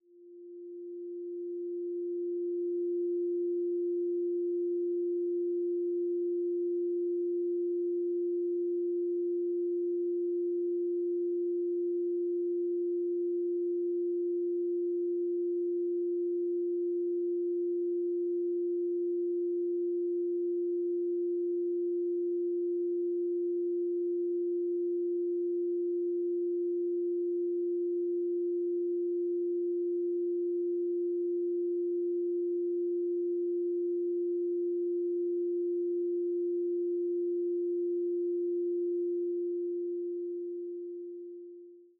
Duration: 0:42 · Genre: Romantic · 128kbps MP3